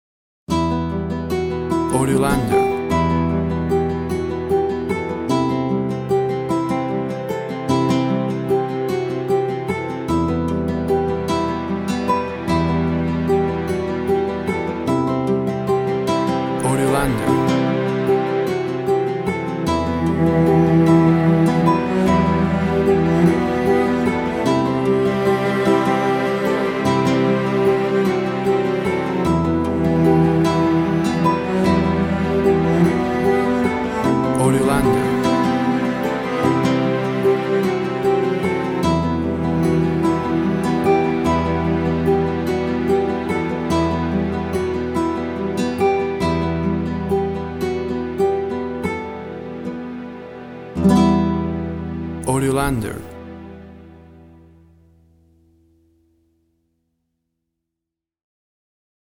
Tempo (BPM) 150